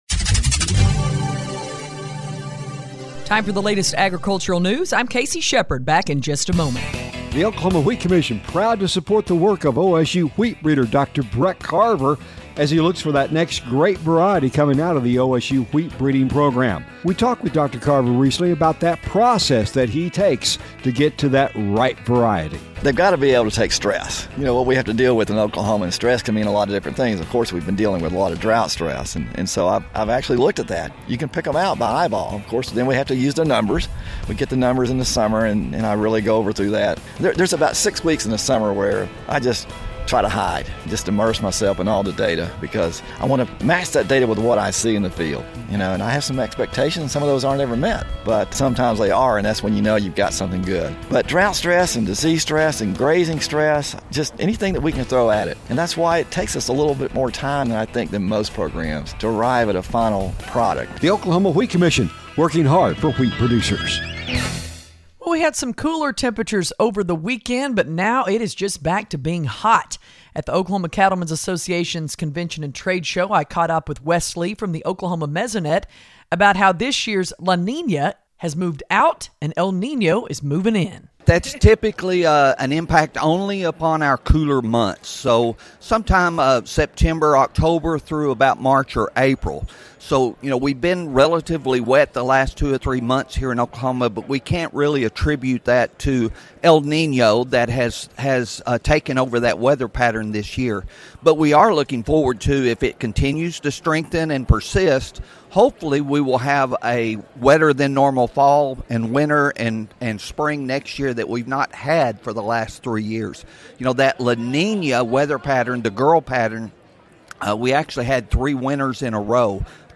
Farm and Ranch News